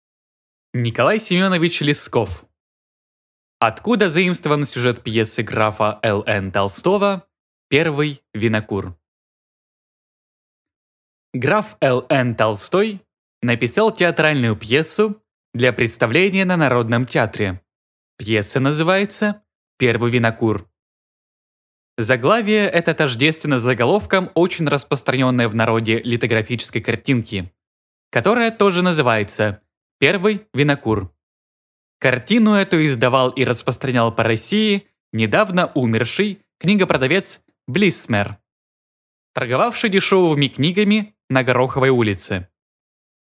Аудиокнига Откуда заимствован сюжет пьесы графа Л. Н. Толстого «Первый винокур» | Библиотека аудиокниг